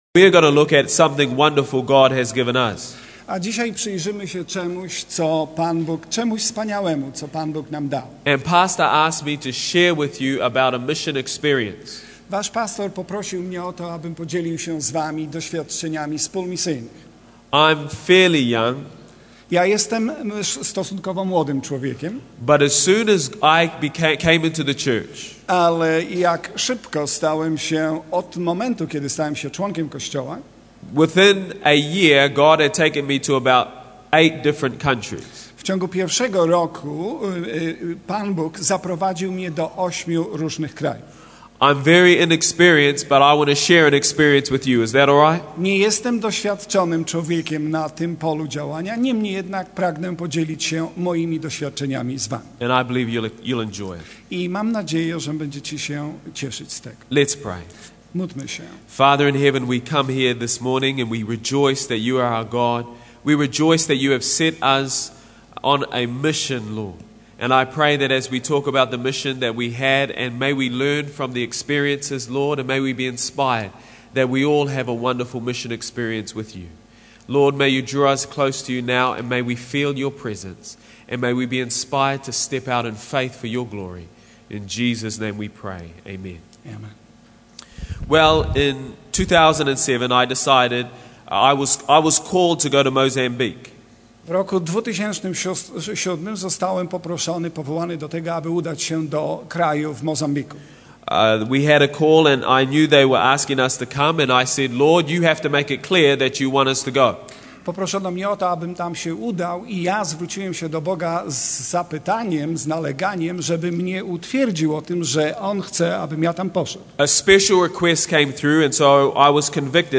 zarejestrowano 26 grudnia 2008 w audytorium Berwick Church of Christ, Melbourne, Australia
recorded 26 grudnia 2008 in the auditorium of Berwick Church of Christ, Melbourne, Australia